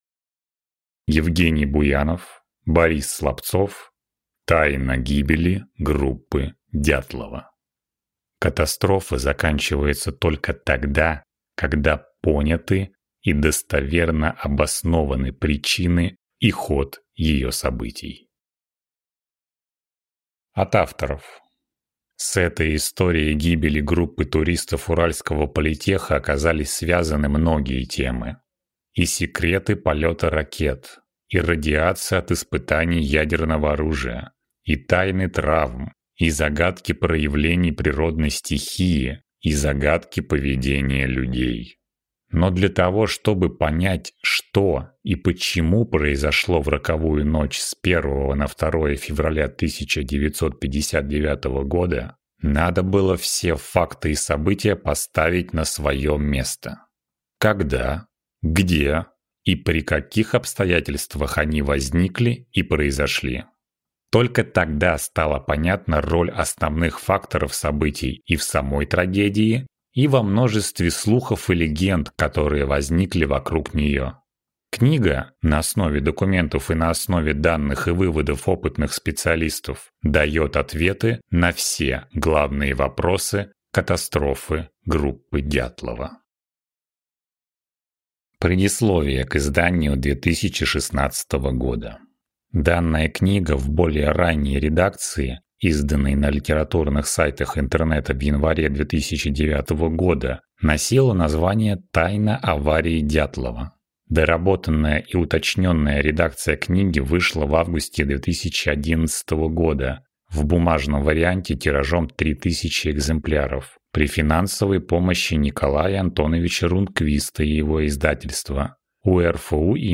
Аудиокнига Тайна гибели группы Дятлова | Библиотека аудиокниг
Прослушать и бесплатно скачать фрагмент аудиокниги